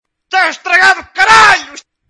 pai do ruca ta estragado o saralho Meme Sound Effect
pai do ruca ta estragado o saralho.mp3